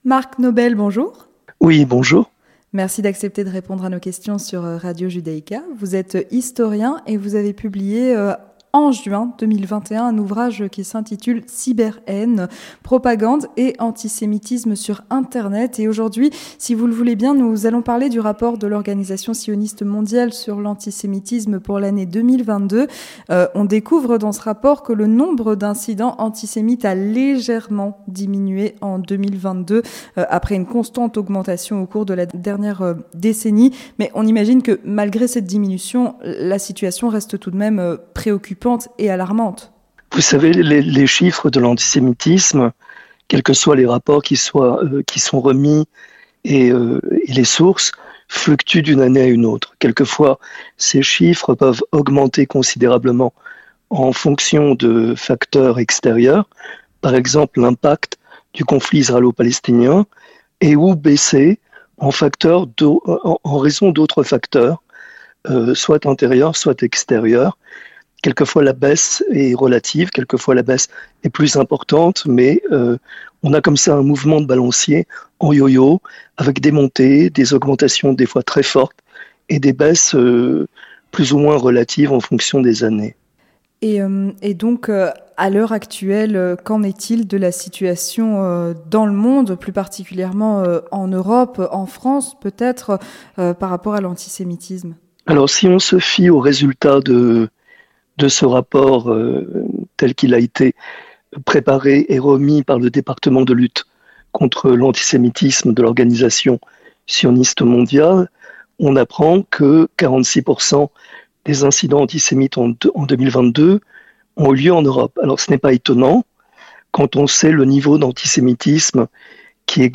Écoutez notre podcast "L'entretien du 18H" au sujet du rapport de l’organisation sioniste mondiale sur l’antisémitisme pour l’année 2022.